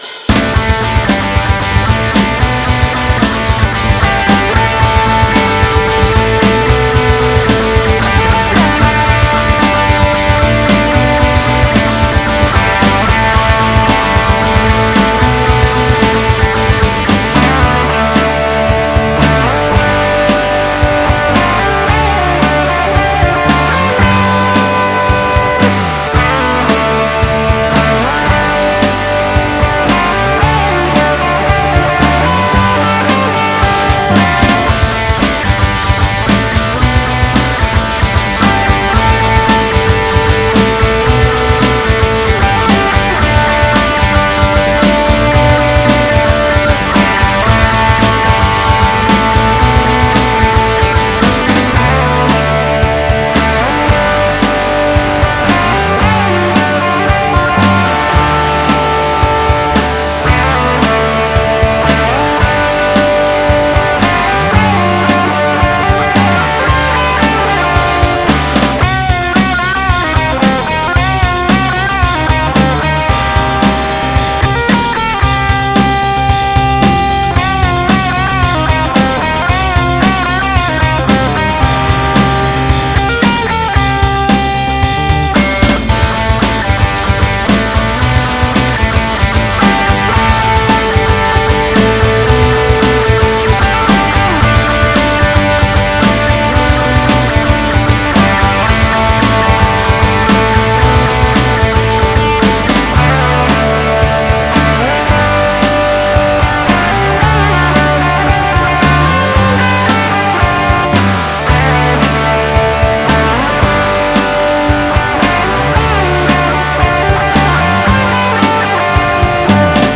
No, I cannot do anything about the general quality of the mix, as I am incompetent.